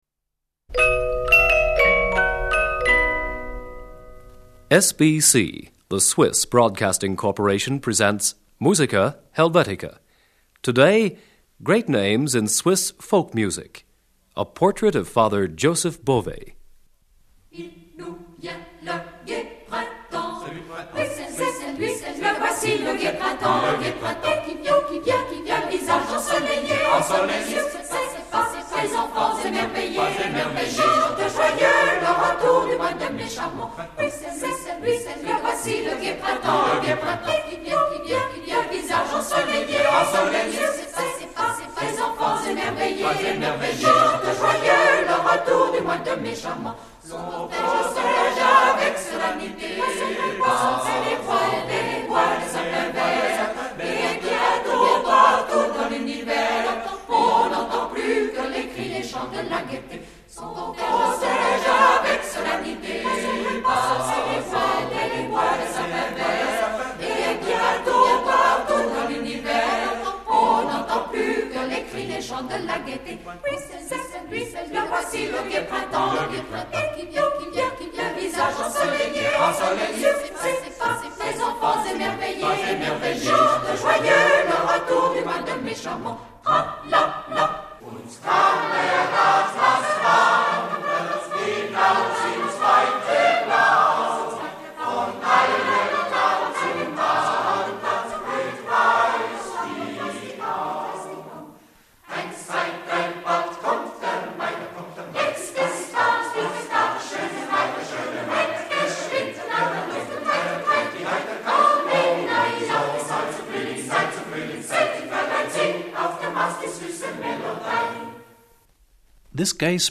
Great Names in Swiss Folk Music. Father Joseph Bovet.
Instrumental Ensemble.
Tenor.